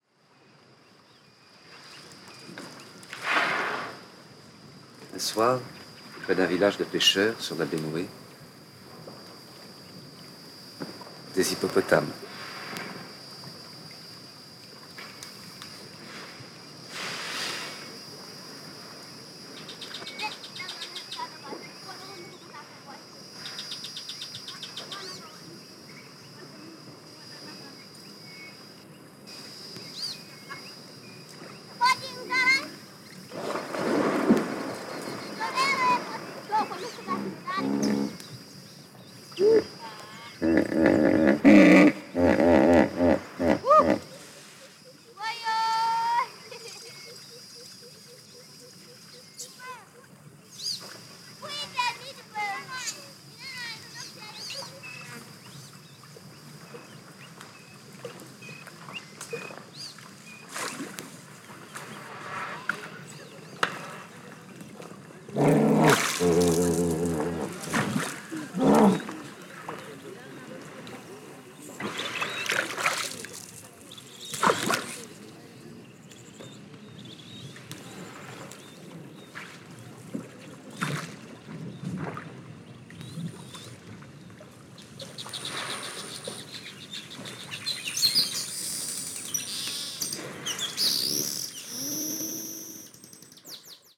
中央アフリカ・カメルーンの情景を収めた